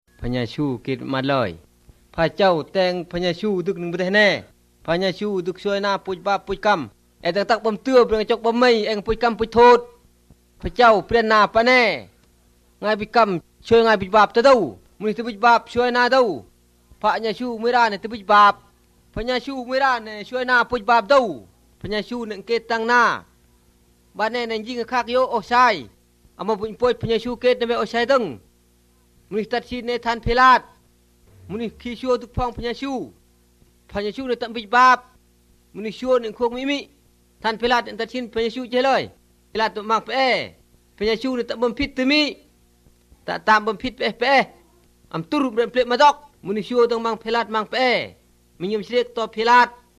The shouting/emphasis at the end of each sentence is a bit unsettling.
This is the Khen Sang dialect.